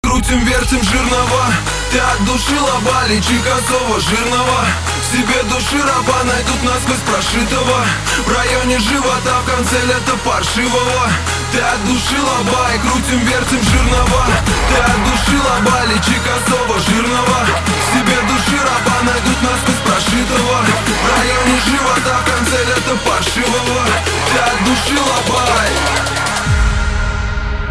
• Качество: 320, Stereo
громкие
русский рэп
house